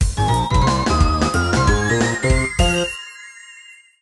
Fair use music sample